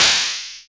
SI2 FM NOIZE.wav